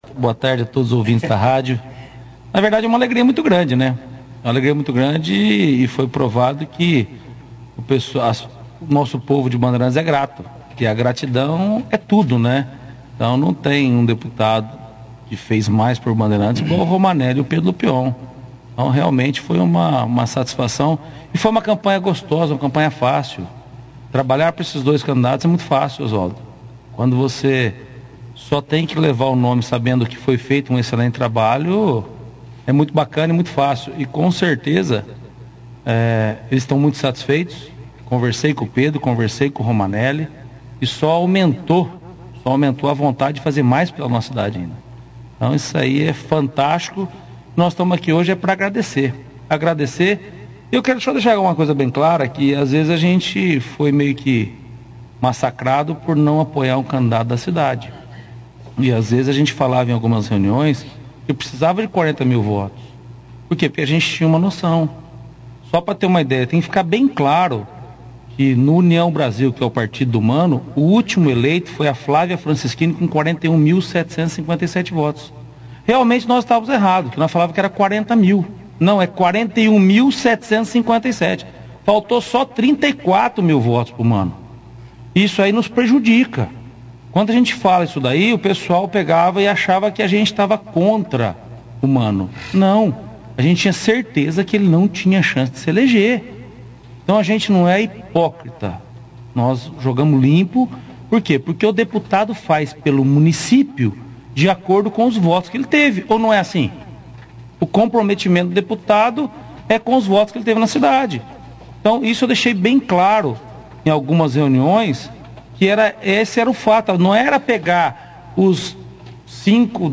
participaram da 2ª edição do jornal Operação Cidade desta terça-feira, 04/10, fazendo um agradecimento aos eleitores, que com seu voto ajudaram a reeleger os candidatos para mais um mandato a Câmara Federal e Assembleia Legislativa, sendo mais uma vez como os mais votados em Bandeirantes.